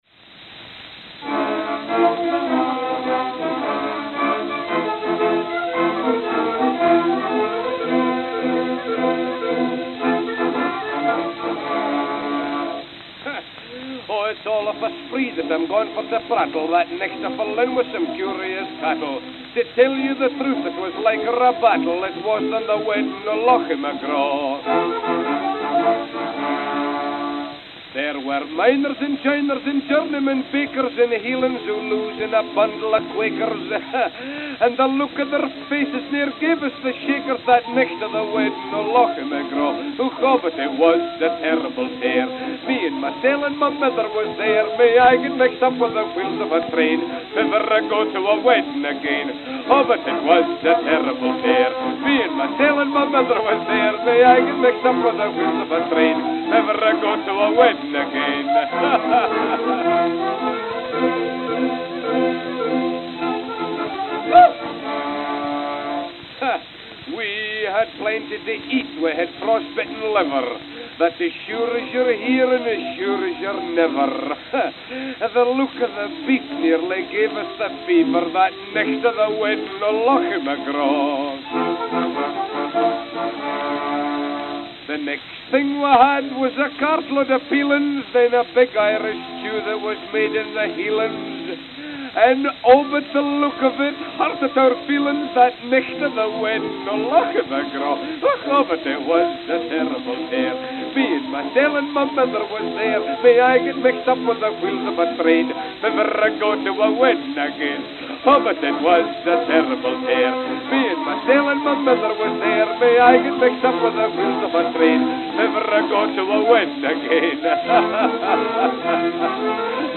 March 1908 (London, England) (1/4)